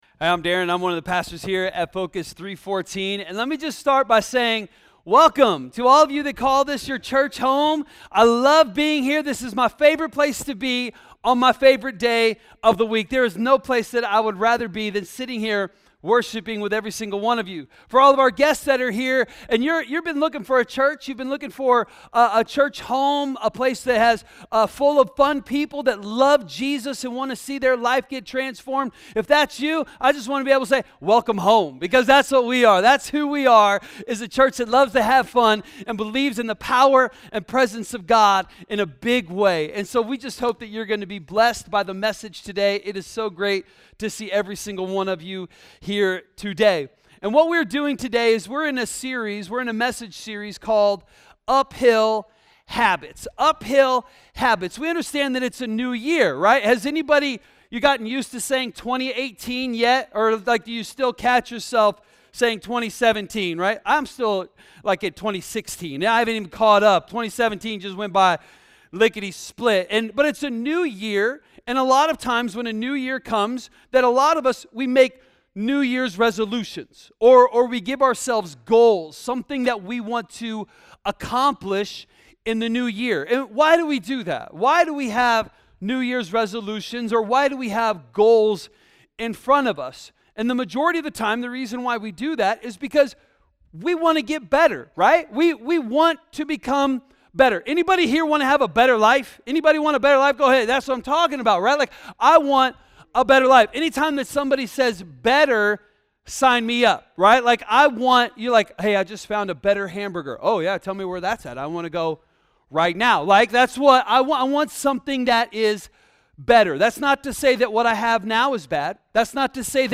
A message from the series "Uphill Habits."